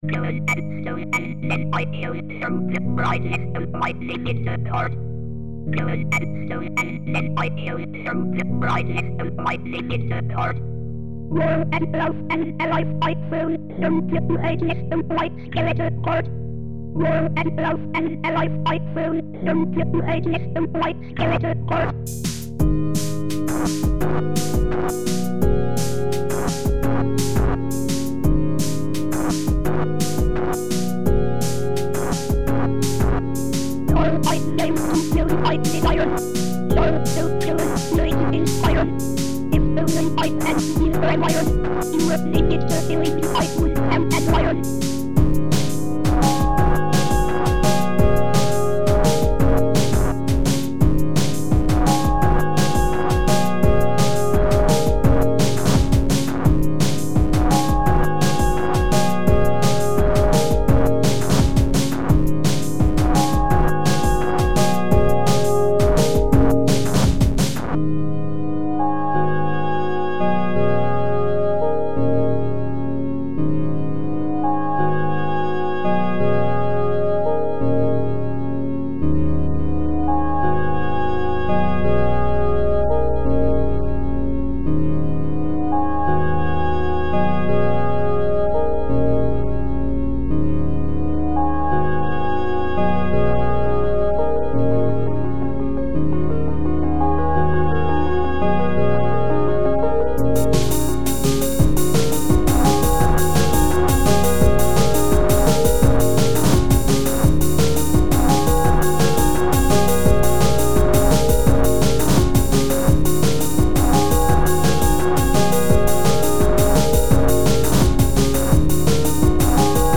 80's d&b/ambient